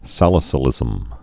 (sălĭ-sə-lĭzəm)